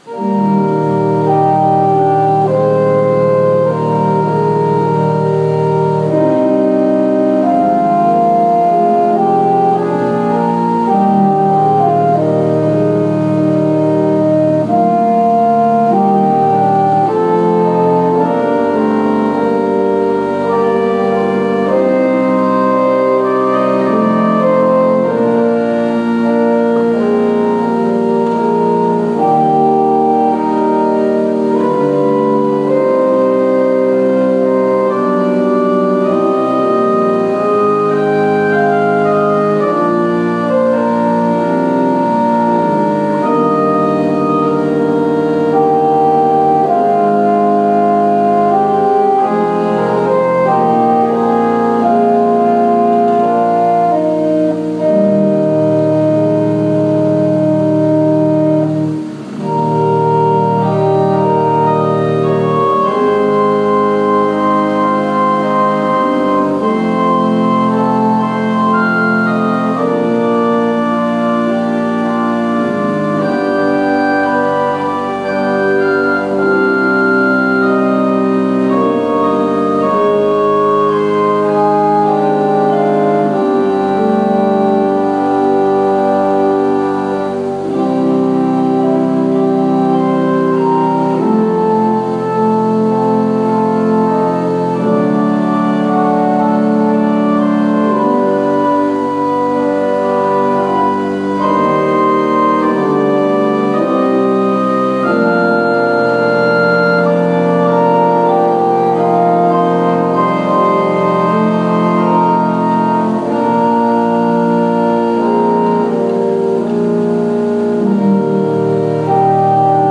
Worship Service June 21, 2020 | First Baptist Church, Malden, Massachusetts